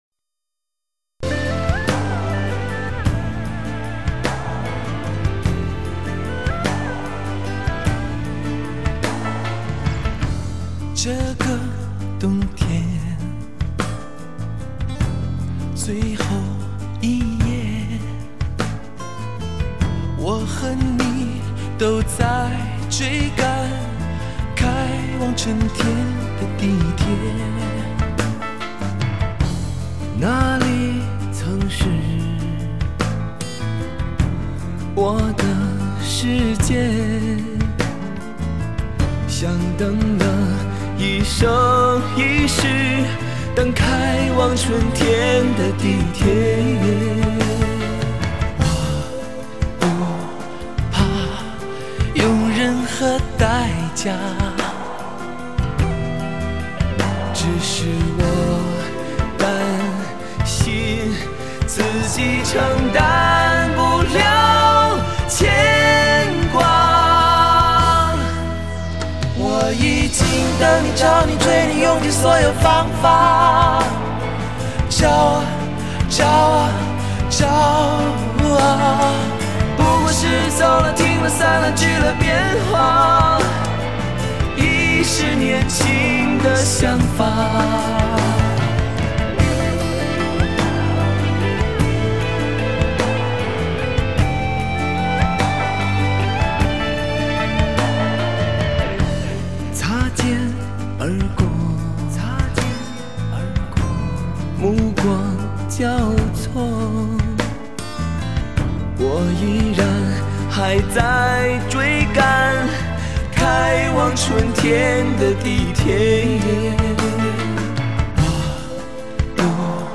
中国大陆最受欢迎的男声音乐组合